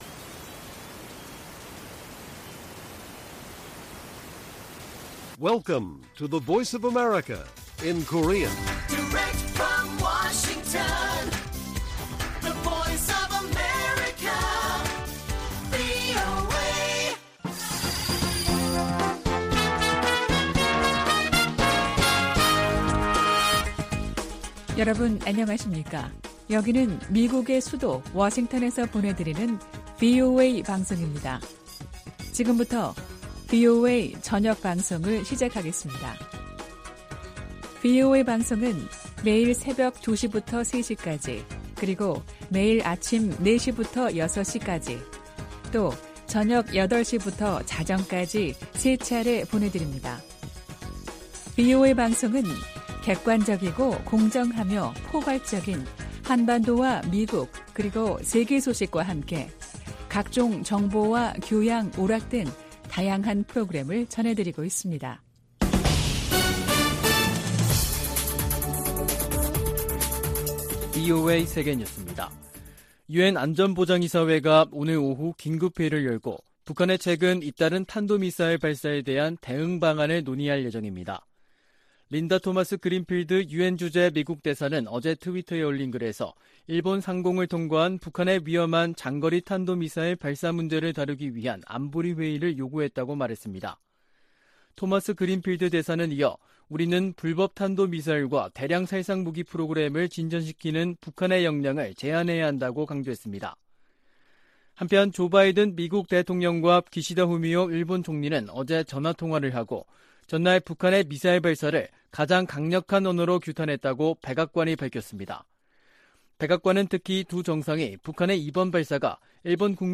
VOA 한국어 간판 뉴스 프로그램 '뉴스 투데이', 2022년 10월 5일 1부 방송입니다. 조 바이든 미국 대통령이 윤석열 한국 대통령에게 친서를 보내 동맹 강화를 강조했습니다. 백악관은 일본 열도를 넘어간 북한의 탄도미사일을 ‘장거리’로 지칭하며 무모한 결정을 강력히 규탄한다고 밝혔습니다.